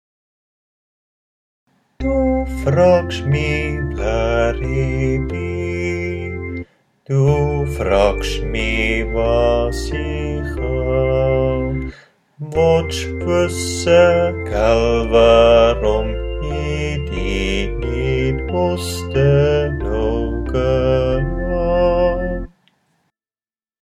5_dufragschmi_bass.mp3